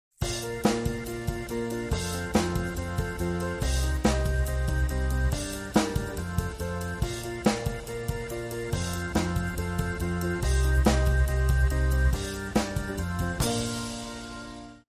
と、ここまで simple 版の仕組み自体は文字どおりとてもシンプルでサービスとしてかなり可能性を感じましたが、完成した曲のクオリティーがまだ乏しいかなといった印象です。
Genre : Classic Rock
Mood : Happy